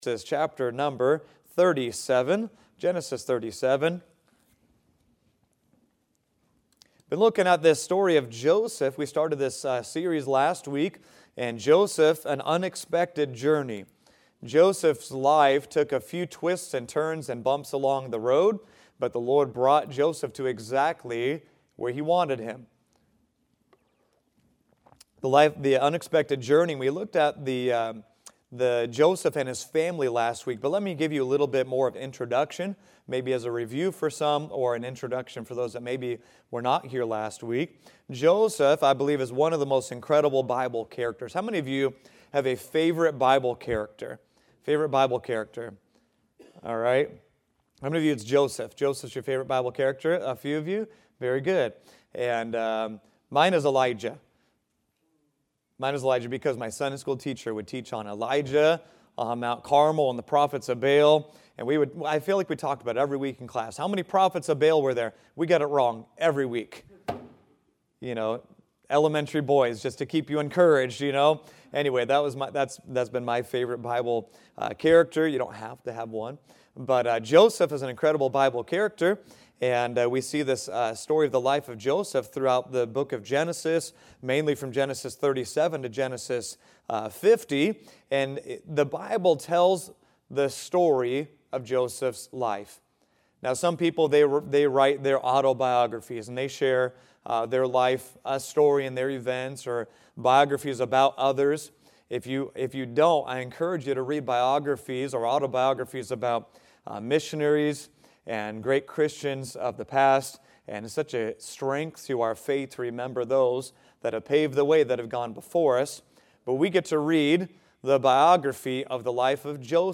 Joseph and His Dreams – Part 1 | Sunday School – Shasta Baptist Church